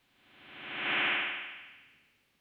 Metallic Draft.wav